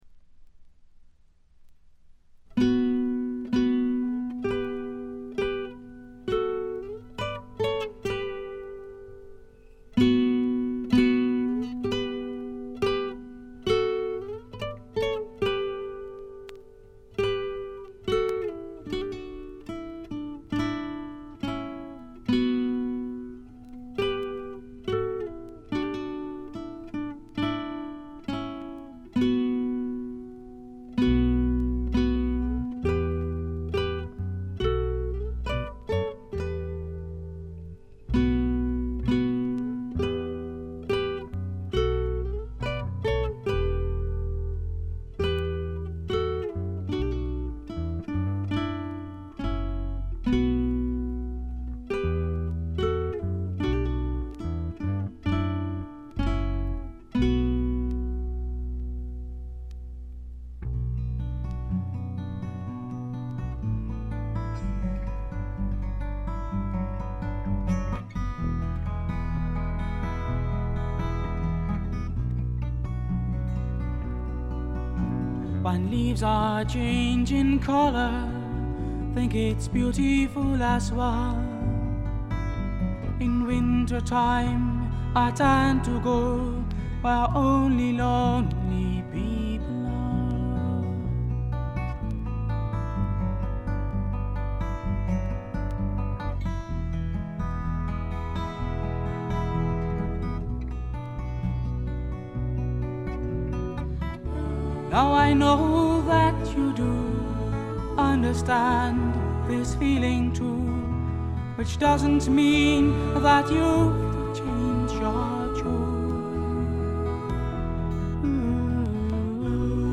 軽いチリプチが少々。
全体を貫く哀愁味、きらきらとしたアコースティックな美しさは文句なしに至上のもの。
試聴曲は現品からの取り込み音源です。